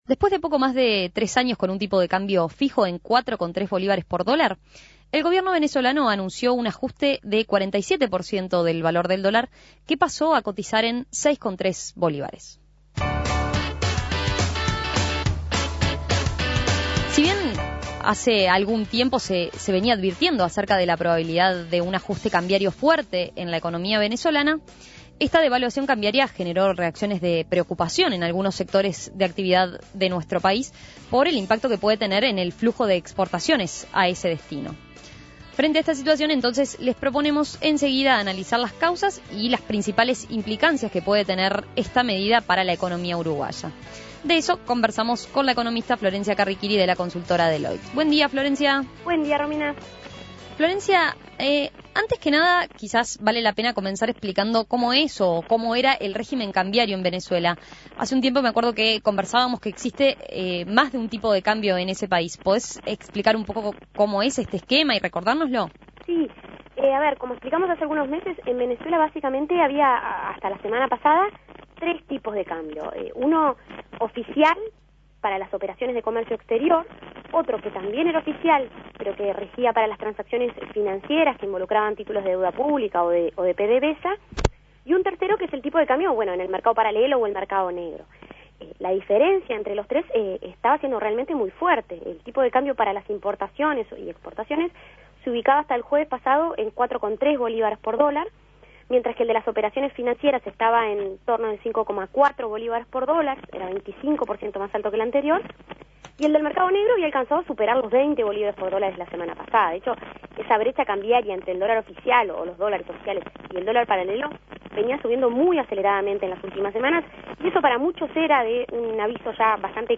Análisis Económico Causas y consecuencias de la devaluación cambiaria en Venezuela